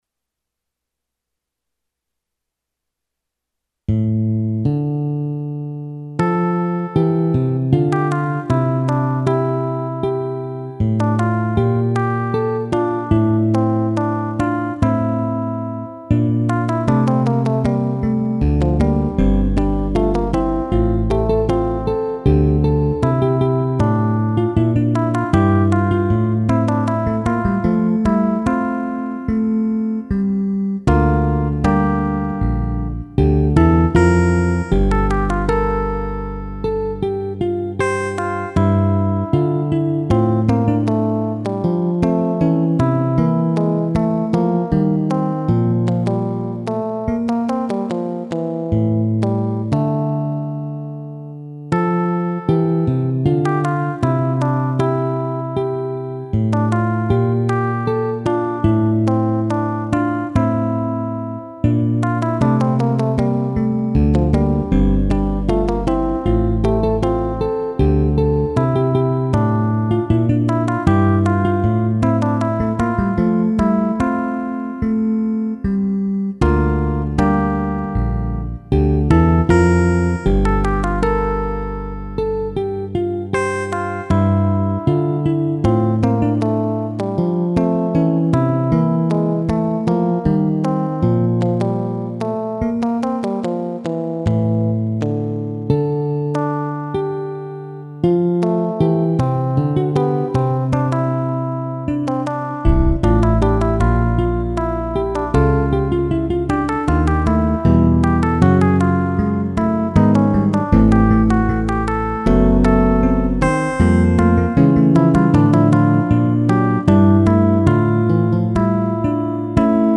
After a bass by Ockeghem, from a motet with that title
CHANSON ; POLYPHONIC MUSIC